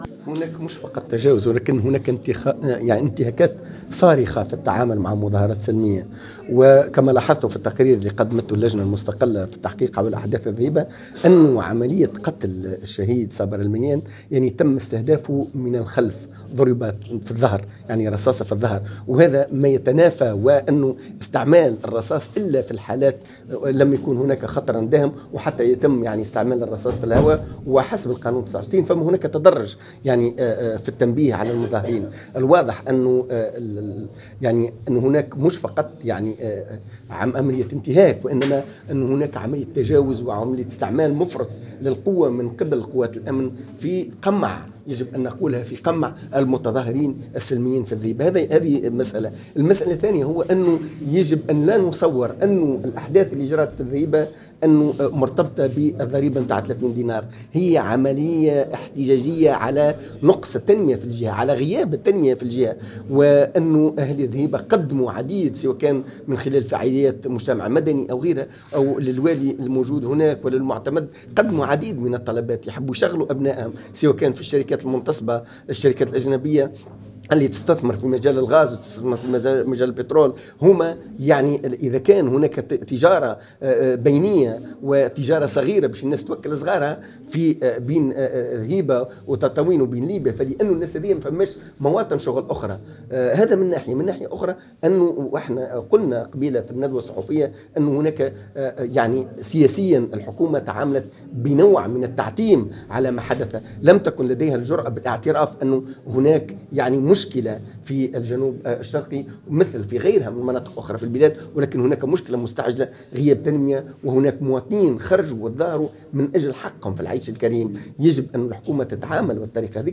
خلال ندوة صحفية عقدتها اللجنة اليوم لتقديم تقريرها بخصوص الأحداث التي جدت هناك مؤخرا